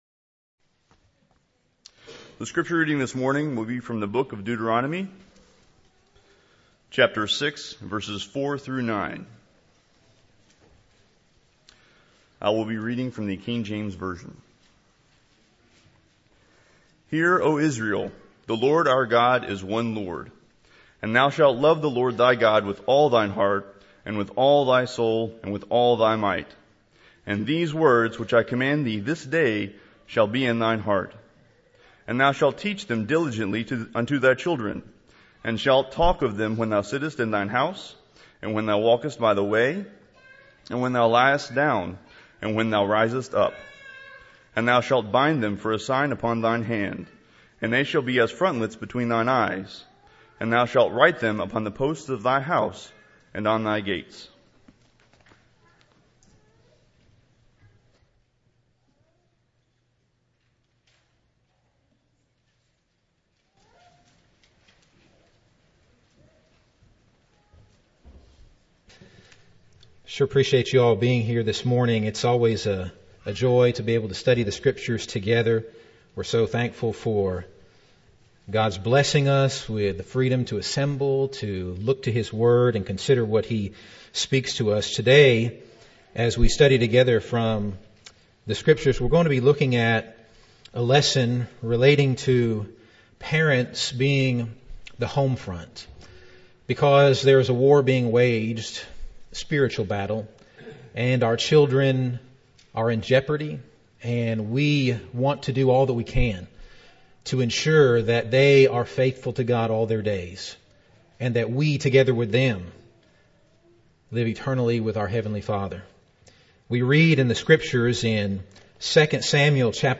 Eastside Sermons Service Type: Sunday Morning Download Files Bulletin « Three Reasons to be a Christian Moses